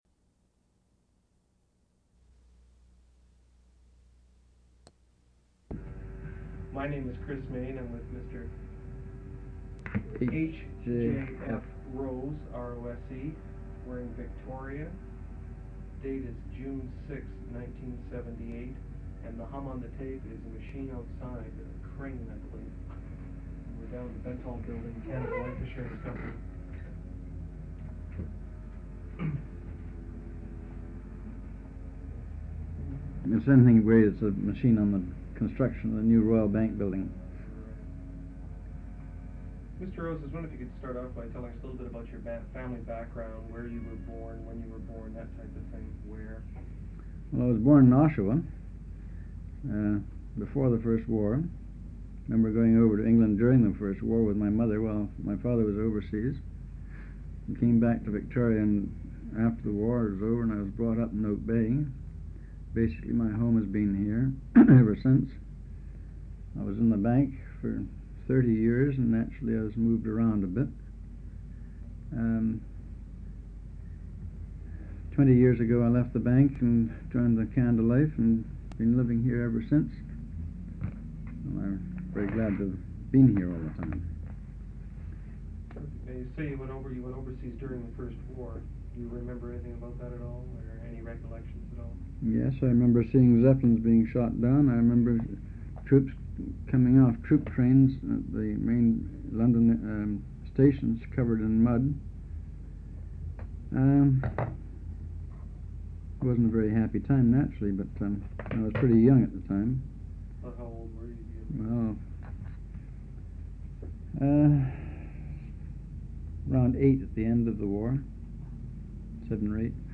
Personal narratives--Canadian